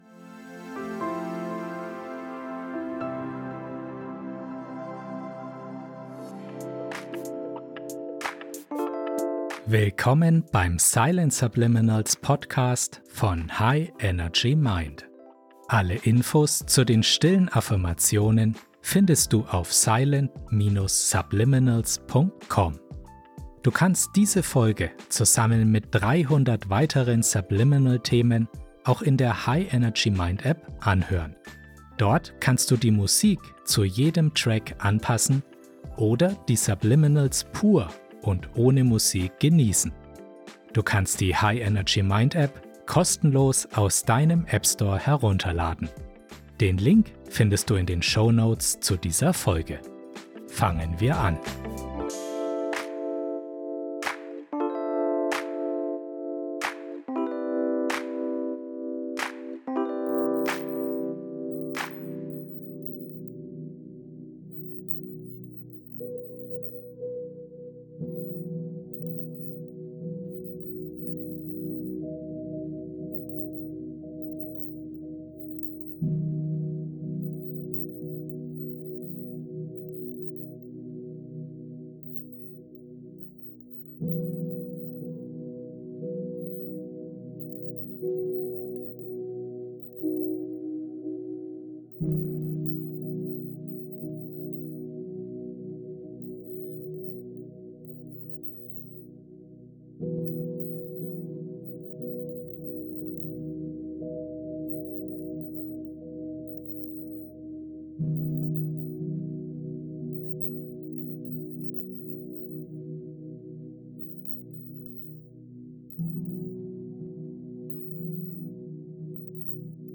432 Hz Entspannungsmusik
Durch regelmäßiges Anhören kannst du Stress reduzieren, innere Anspannung lösen und einen Zugang zu tiefer Entspannung entwickeln. Lass die sanften Klänge und stillen Botschaften auf dich wirken, um Gelassenheit in deinen Alltag zu integrieren.